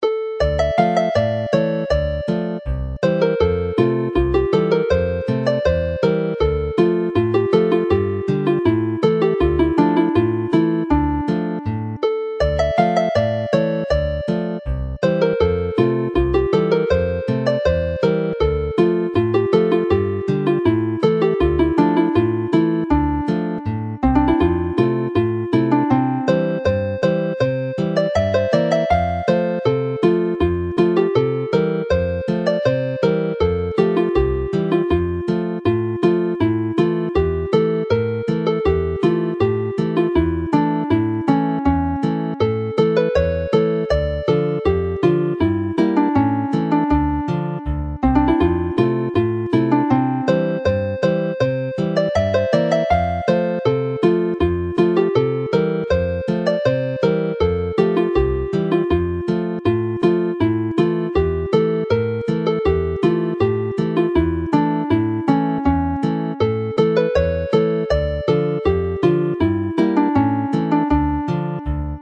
Alawon Cymreig - Set Sawdl y Fuwch - Welsh folk tunes to play -